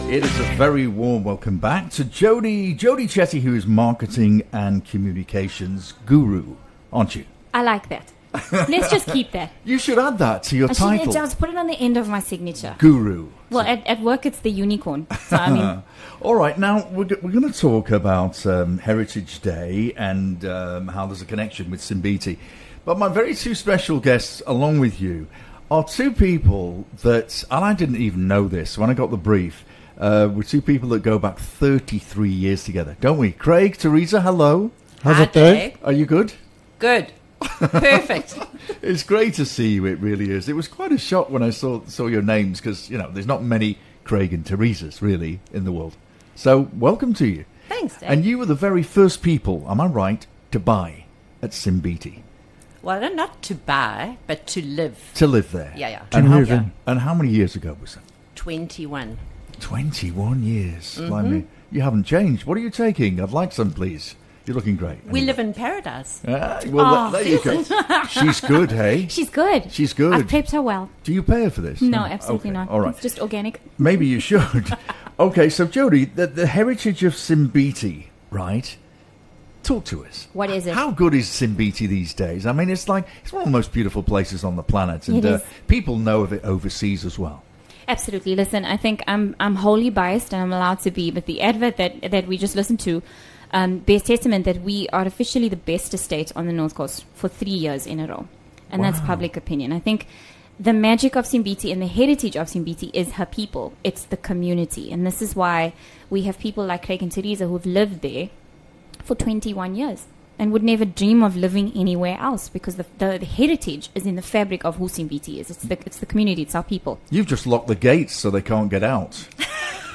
Broadcasting live from the heart of Ballito, The Afternoon Show serves up a curated mix of contemporary music and hits from across the decades, alongside interviews with tastemakers and influencers, and a healthy dose of local news & views from the booming KZN North Coast.